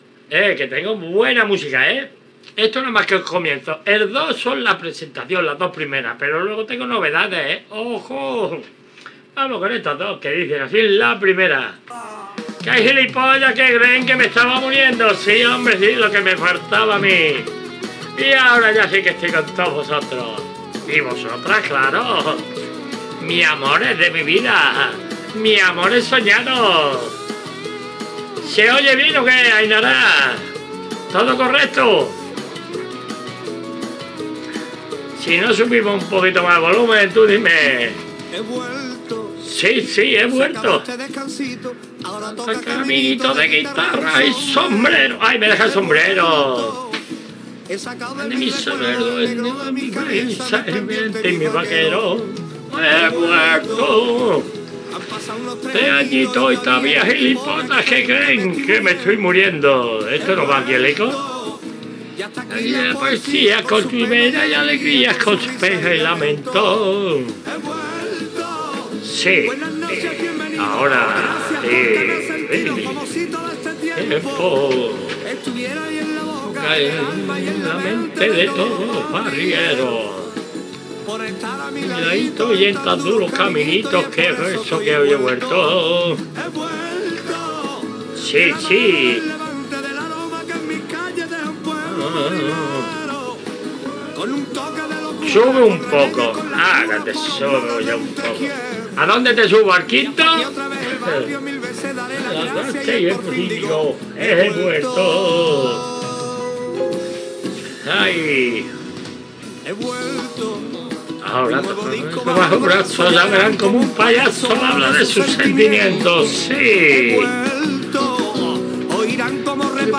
Inici de l'audiència amb la salutació a l'audiència de Facebook i la felicitació a un col·lega de Can 10 FM